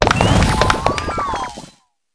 chiyu_ambient.ogg